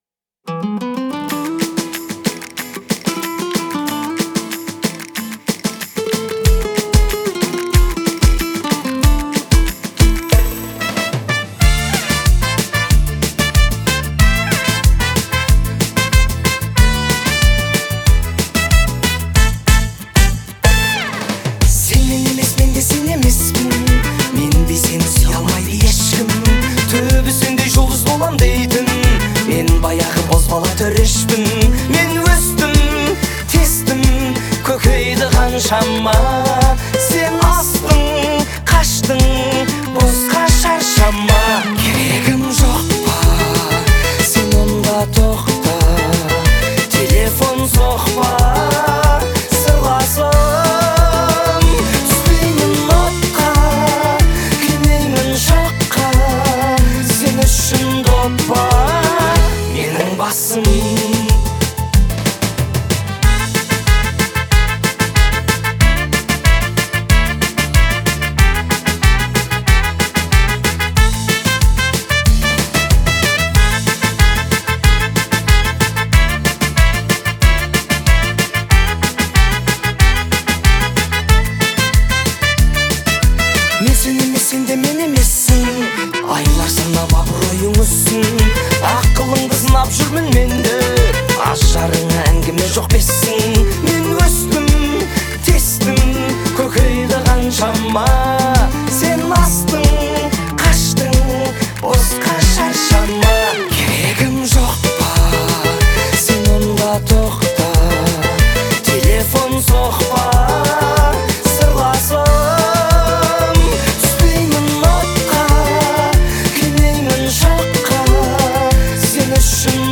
яркая и эмоциональная песня казахского исполнителя